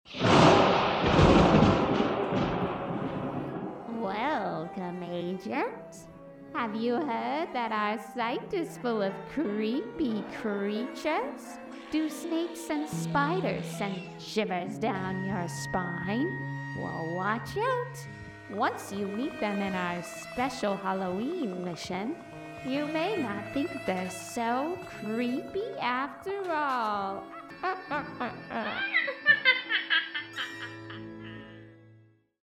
The Mission also features a spooky and fun welcome message.
Generic-Halloween-Mission-Voiceover-Download-_-Use-it-for-your-Mission-today.mp3